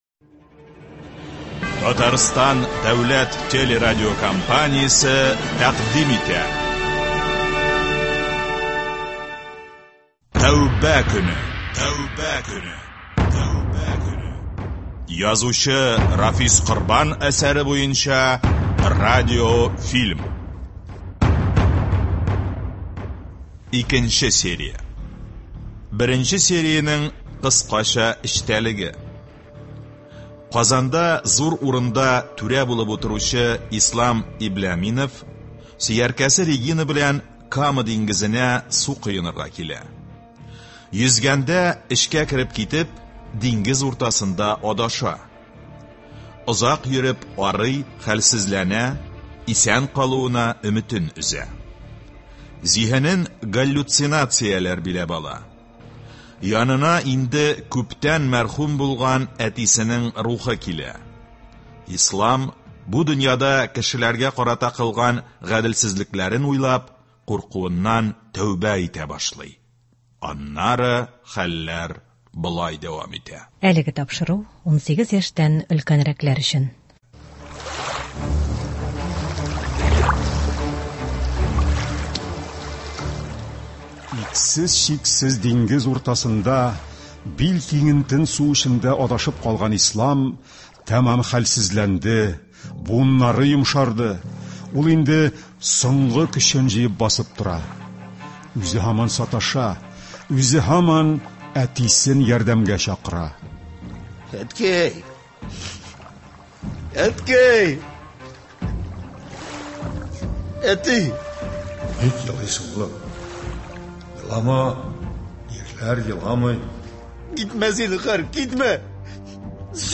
Танылган шагыйрь, галим, үзенең шигырьләренә композитор буларак та үзенчәлекле аһәңгә ия булган күп кенә көйләр язган, күпкырлы иҗат иясе Хәнәфи Бәдигый әсәрләреннән төзелгән “Яран гөл” дигән әдәби-музыкаль композиция тыңларга чакырабыз.
Тапшыруның икенче өлешендә радиобызның алтын фондыннан бик кадерле бер язма тәкъдим итәбез.
Заманында танылган язучы Татарстан радиосына килепе үзенең “Кыз урлау” дигәзн әсәреннән бер өзек укыган.